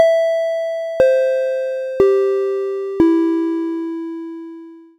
4-tone chime DOWN
bell chime ding microphone pa ping ring sound effect free sound royalty free Sound Effects